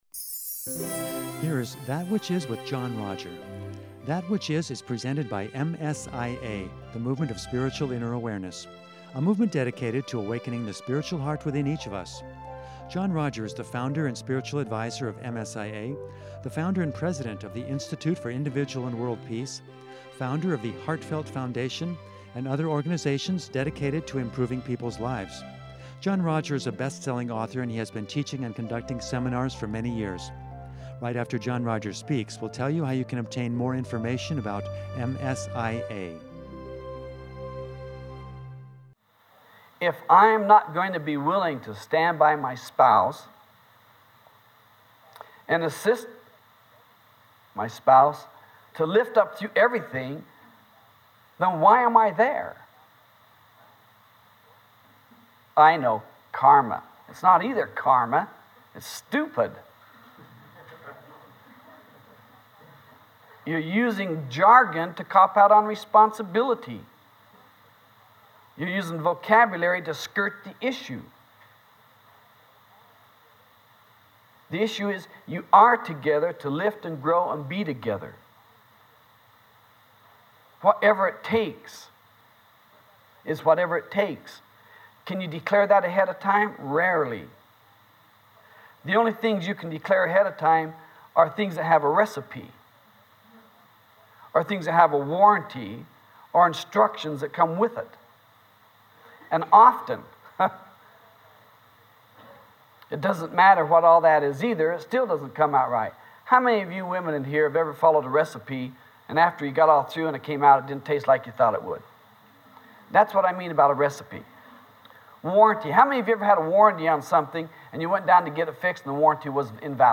This seminar is rich with potential for you and me.